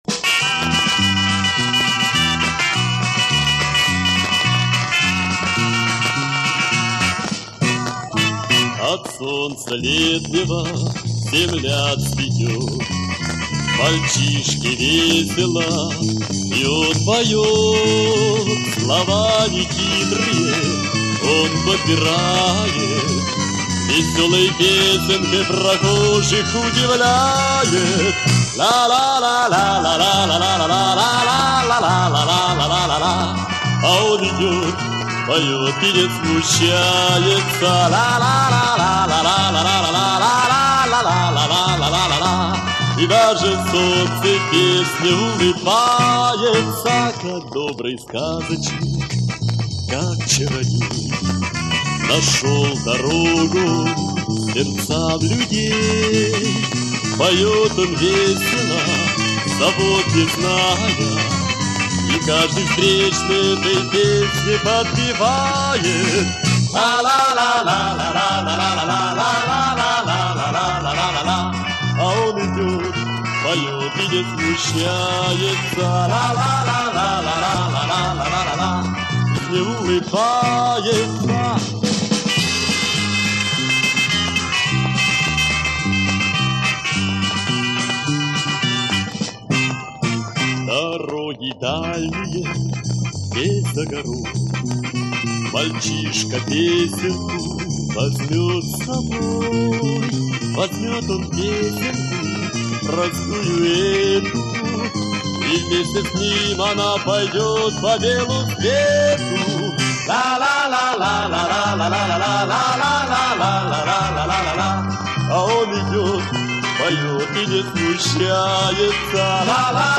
Формат: Vinil, EP, Mono, 33 ⅓, Album
Стиль: Pop Rock, Schlager